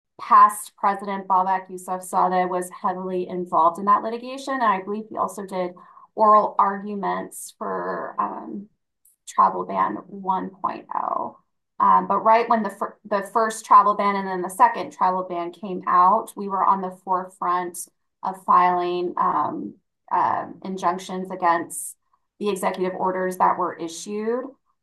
Interview Clips